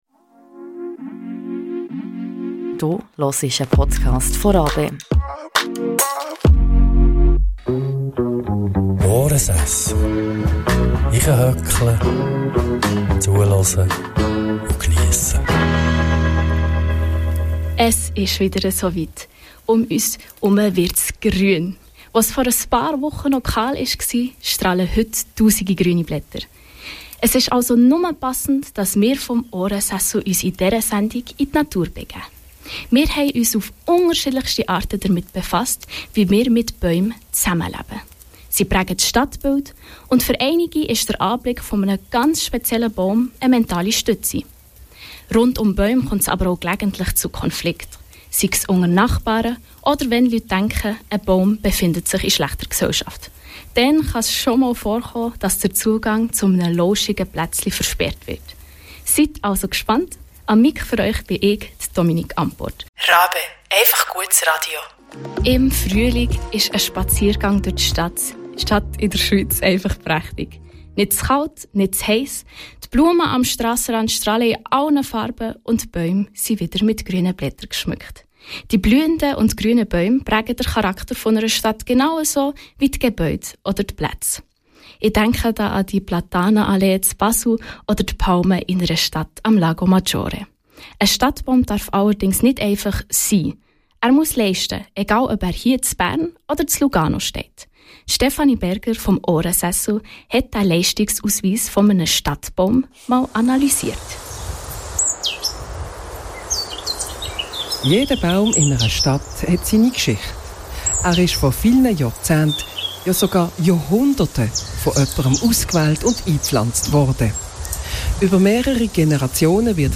Der Podcast von Ohrensessel ist wie immer ein Zusammenschnitt einer live-Sendung von Radio Bern RaBe, produziert und präsentiert von den Praktikant:innen des radiojournalistischen Lehrganges.